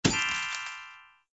Converted sound effects
SZ_DD_treasure.ogg